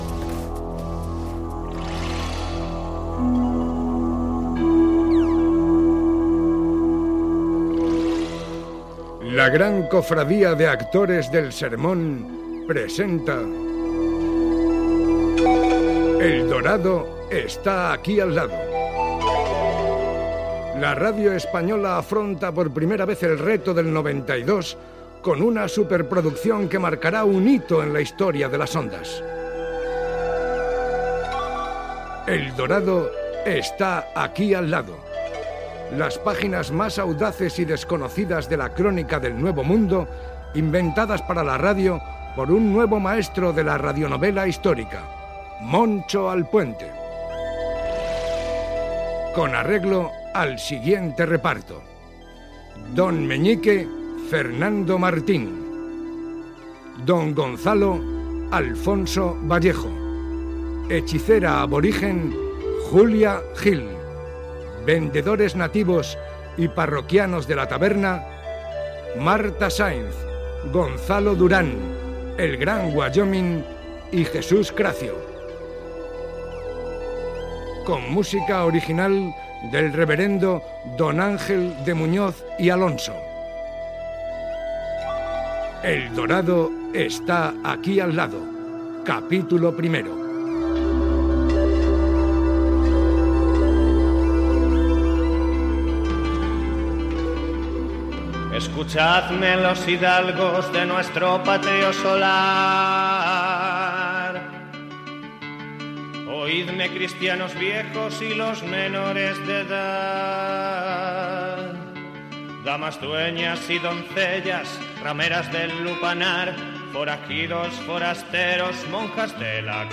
Primer capítol del serial "El dorado está aquí al lado". Careta de l'espai amb el repartiment, cançó per obrir la història, el narrador situa l'acció que transcorre durant el descobriment d'Amèrica
Entreteniment